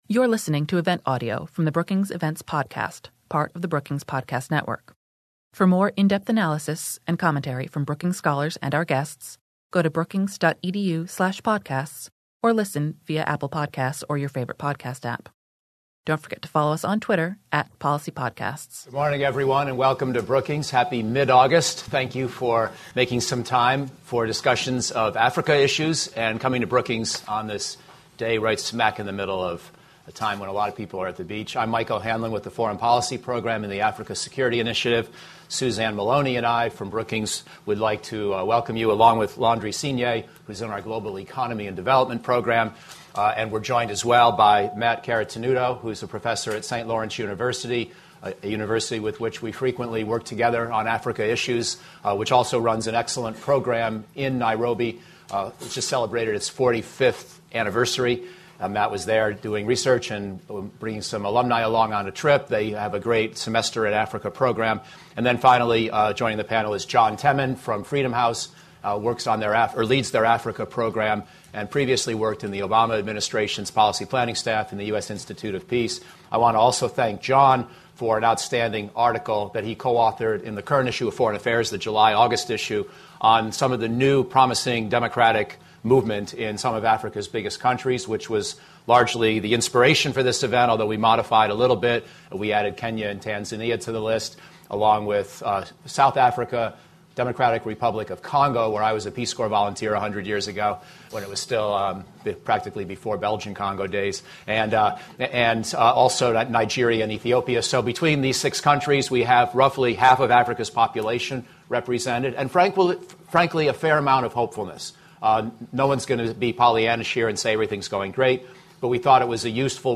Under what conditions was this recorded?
On August 12, the Africa Security Initiative at Brookings hosted an event examining the security status of Ethiopia, Nigeria, the Democratic Republic of the Congo, South Africa, Kenya, and Tanzania.